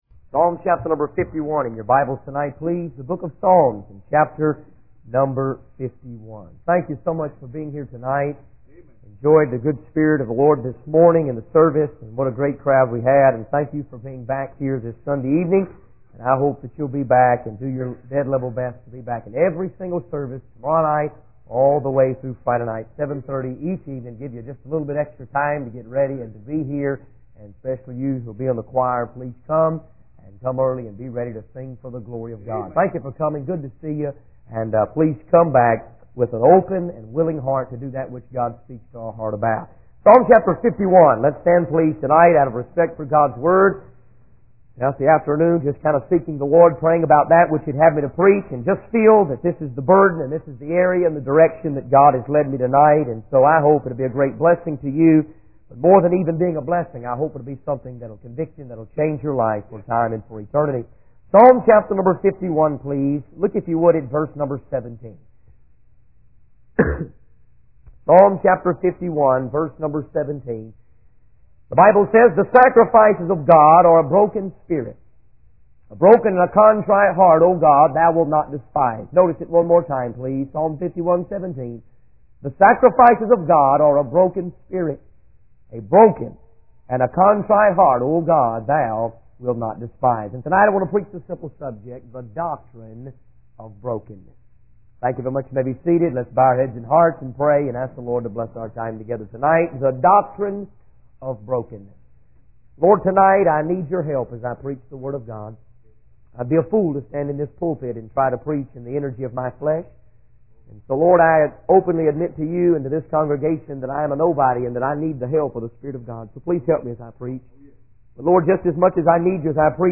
In this sermon, the preacher discusses why people are not being converted to Christianity as they used to be. He believes that God's people have lost their burden for the lost and have become too focused on their own entertainment and schedules.